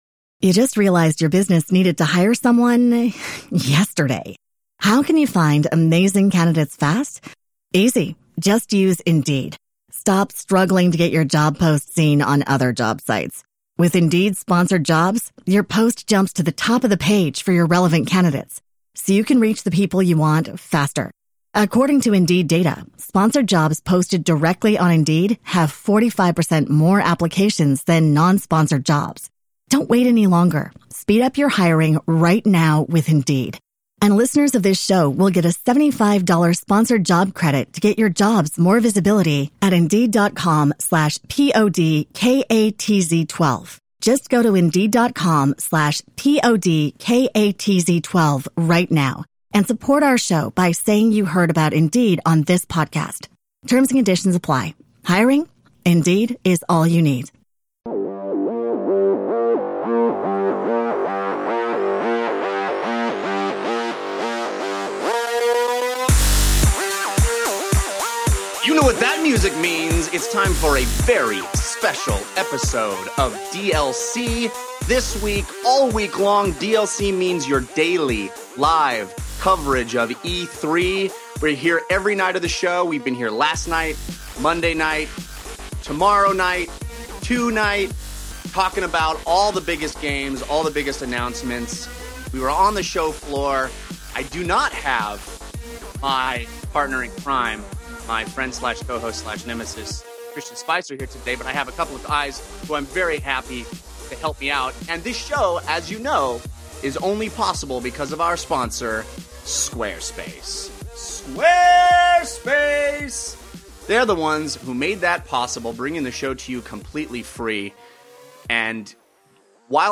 All that, plus YOUR phone calls!